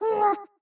Minecraft Version Minecraft Version snapshot Latest Release | Latest Snapshot snapshot / assets / minecraft / sounds / mob / happy_ghast / hurt2.ogg Compare With Compare With Latest Release | Latest Snapshot